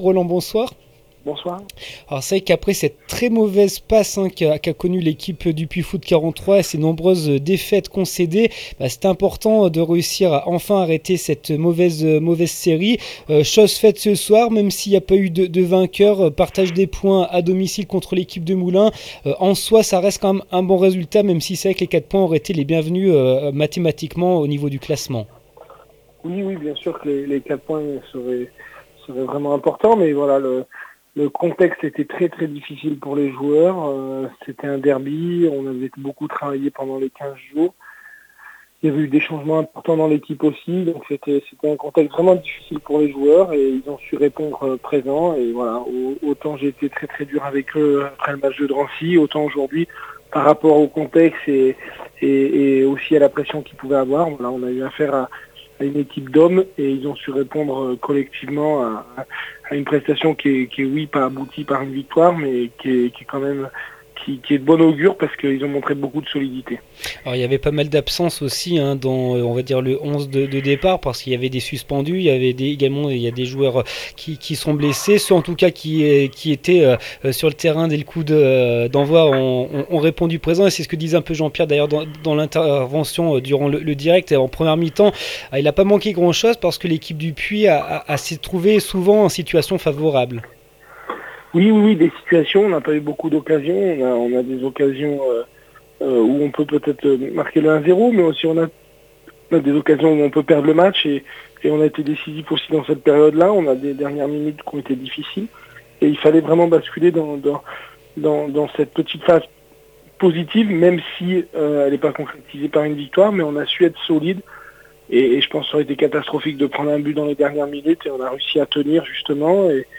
13 décembre 2015   1 - Sport, 1 - Vos interviews, 2 - Infos en Bref   No comments